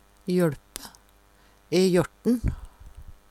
jøLpe - Numedalsmål (en-US)